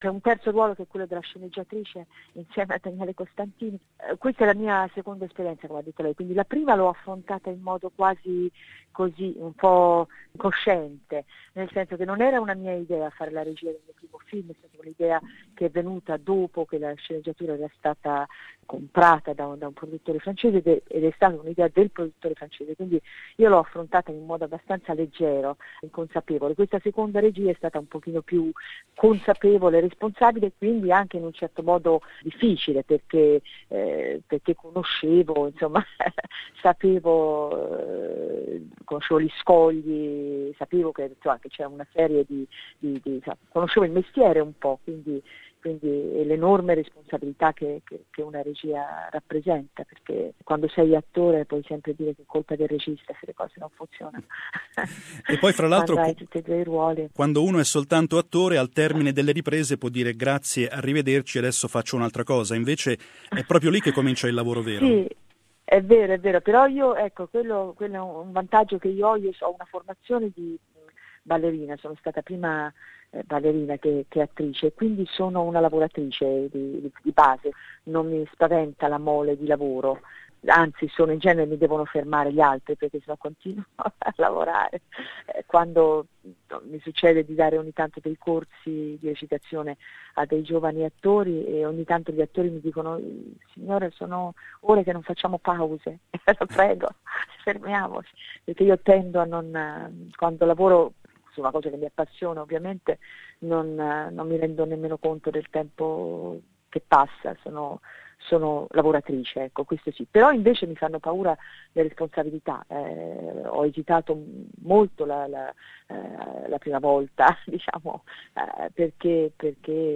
An interview with Laura Morante, actress and director